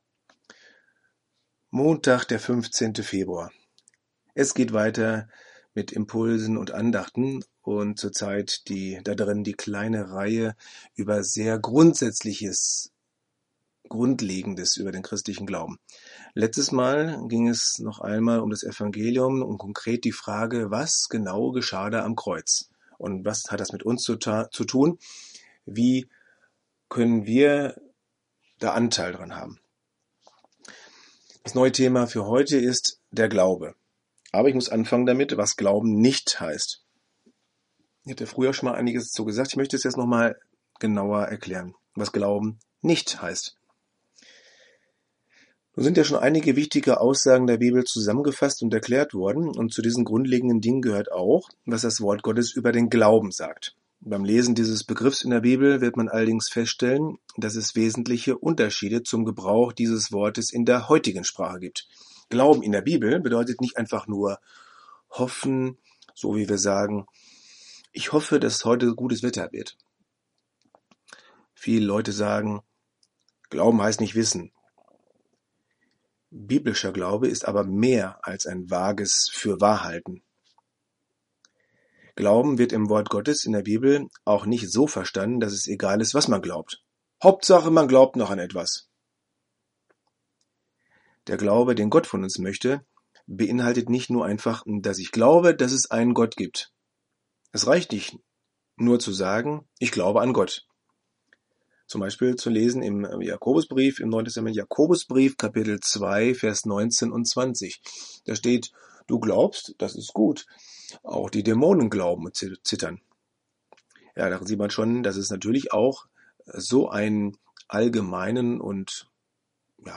Audio-Andachten: Impulse, Denkanstöße über Gott und die Welt